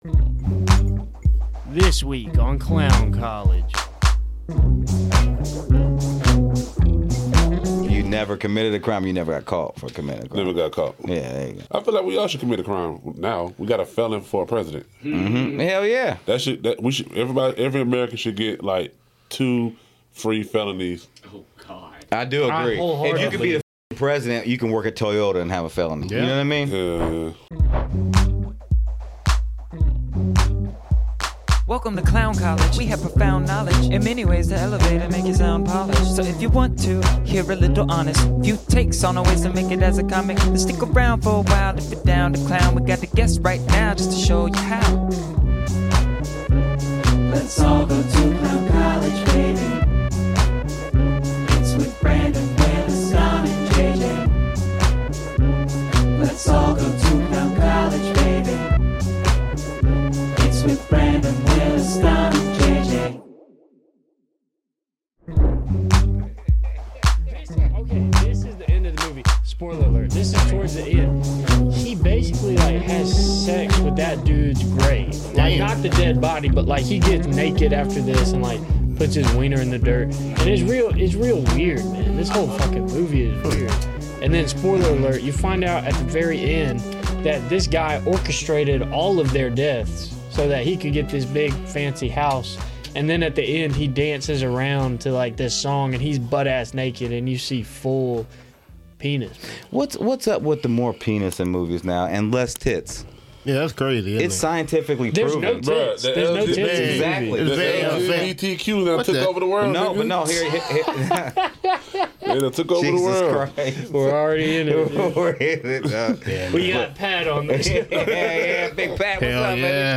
on the 1's and 2's. Get ready to hear about comedy in a way I guarantee you never have before. Reactions, interviews, skits, you name it.